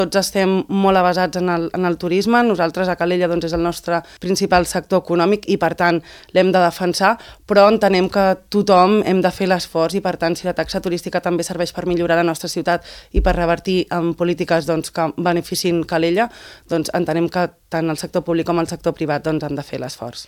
Ho ha explicat a l’espai ENTREVISTA POLÍTICA de Ràdio Calella TV, on s’ha alineat amb el posicionament de Buch respecte a la necessitat de lleis més dures per combatre la multireincidència.